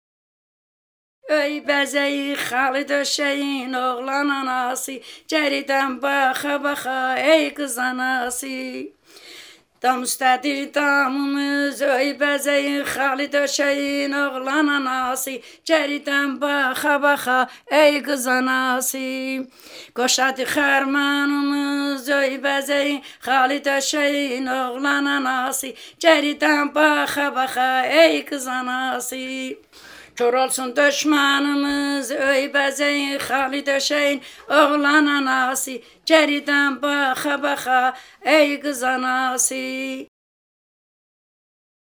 The tunes of one move on the notes of the (sol’-fa)-mi-re-do chords, are performed parlando, their lines end on re or do—thereby resembling the small form of the Hungarian and Anatolian laments.
Example 5. Two Ionian Azeri laments with short sections and small compass